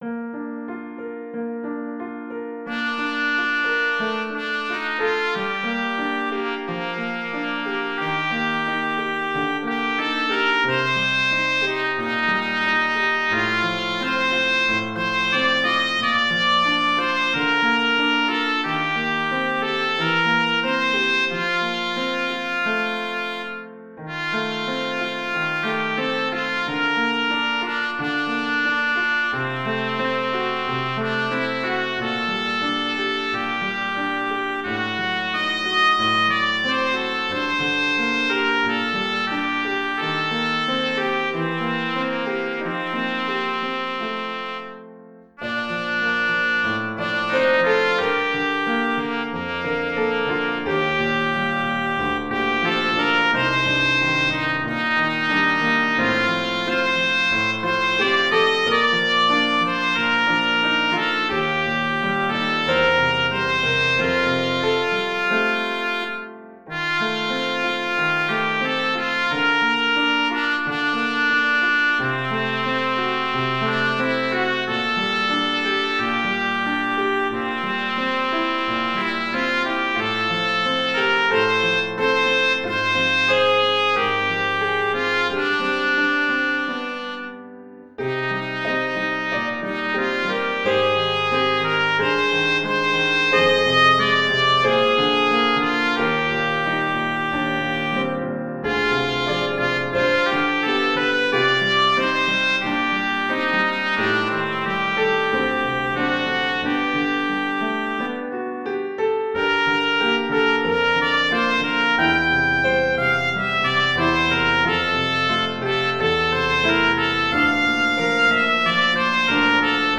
Romantic trumpet sheet music, trumpet and piano.